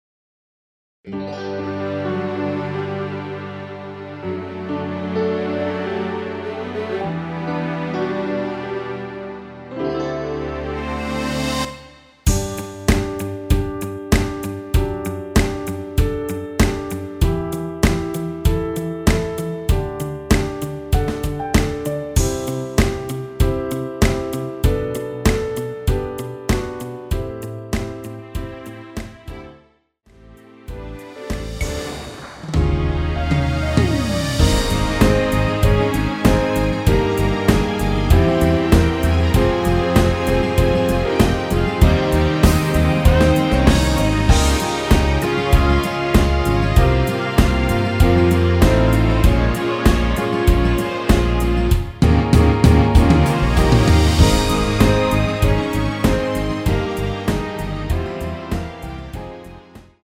앨범 | O.S.T
앞부분30초, 뒷부분30초씩 편집해서 올려 드리고 있습니다.
중간에 음이 끈어지고 다시 나오는 이유는